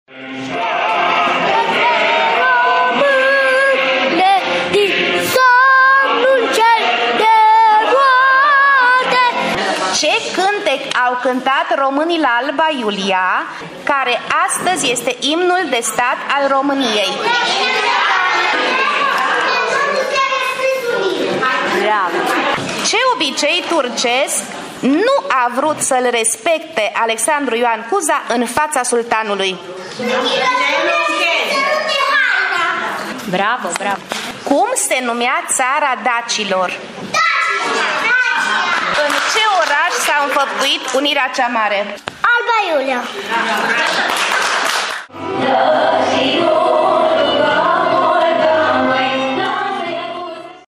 Muzeul de Etnografie din Tîrgu-Mureș a găzduit, zilele trecute, activitatea de închidere a proiectului educațional pentru preșcolarii din județul Mureș, ”Mândru sunt că sunt român”.
Concursul a beneficiat și de un moment folcloric